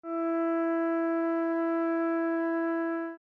E4.mp3